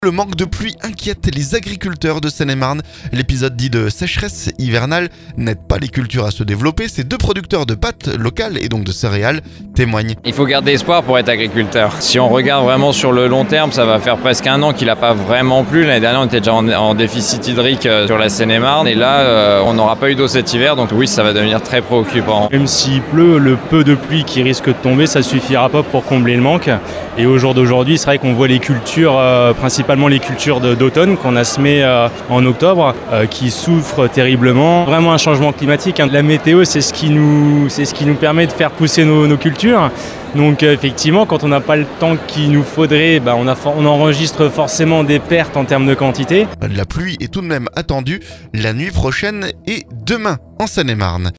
Le manque de pluie inquiète des agriculteurs de Seine-et-Marne. L'épisode dit de sécheresse hivernale n'aide pas les cultures à se développer. Ces deux producteurs de pâtes locales, et donc de céréales, témoignent.